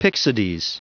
Prononciation du mot pyxides en anglais (fichier audio)
Prononciation du mot : pyxides